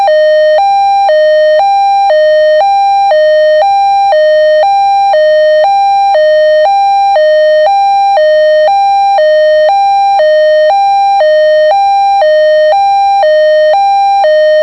Edwards Hi-Lo Tone: